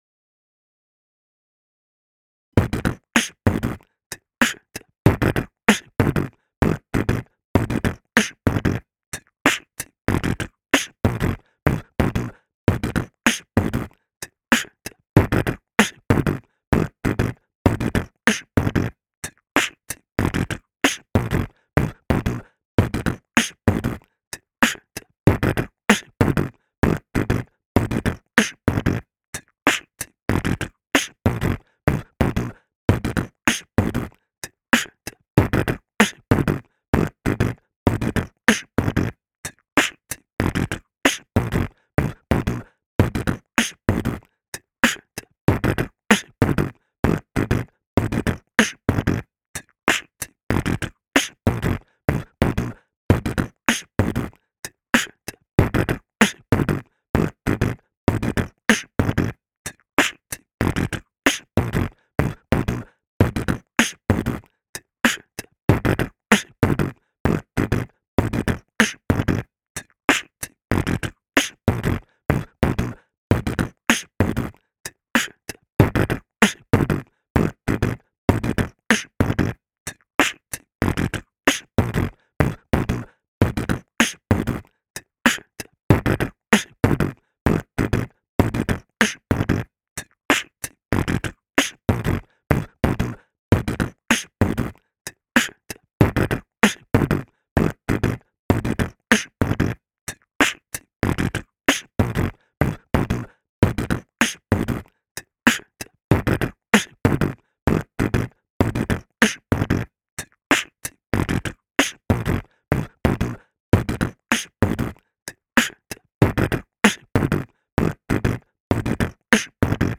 Pierwszy z loopów. Po prostu włącz, aby pobeatboxować sobie razem z tym bitem. W ramach ćwiczeń możesz poskreczować lub dograć nową linię melodyczną do istniejącego bitu, a w drugiej części, gdzie jest sam bas, dodajesz własny beatbox.
loop 1 (4/4, 95bpm, 6:52min, 6.59mb)